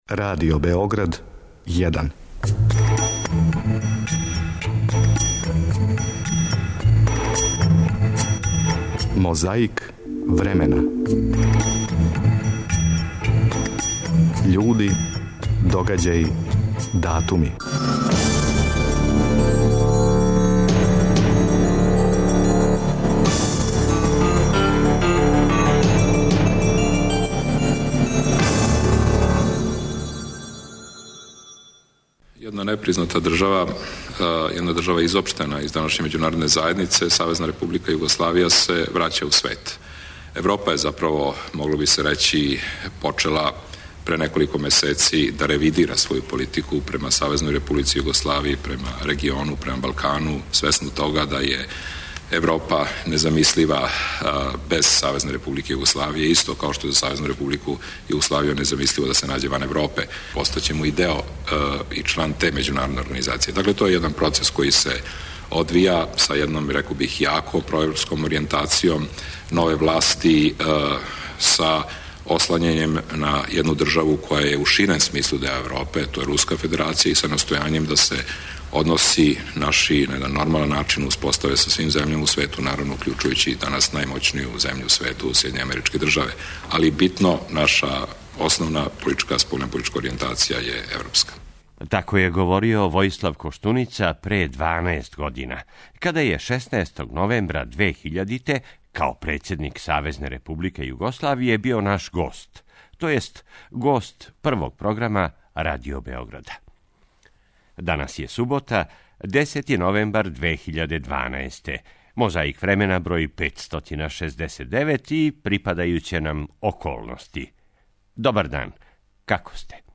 На пример како се 17. новембра 1990. песмом и причом представила Странка демократске акције на таласима нашег програма.
Певало се на радним акцијама надахнуто и смењивало се са скандирањем највећем сину наших народа и народности.
Зборио Тито надахнуто.